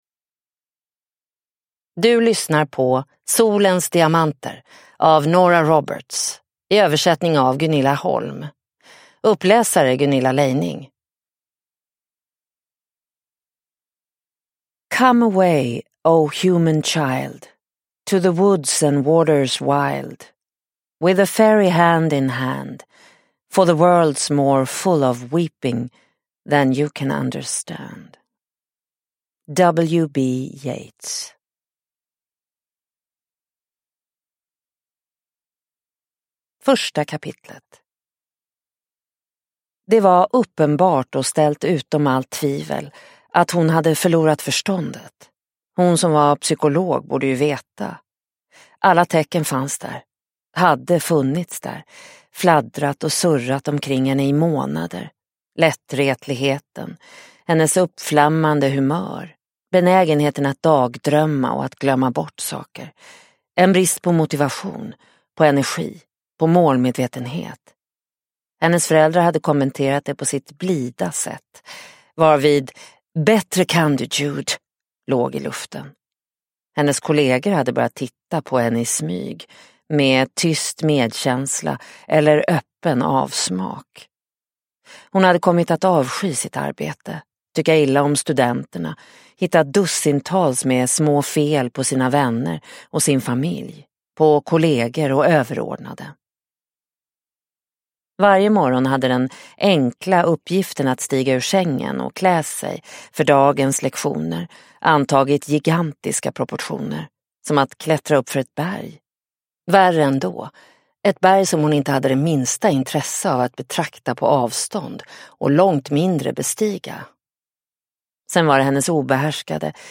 Solens diamanter – Ljudbok – Laddas ner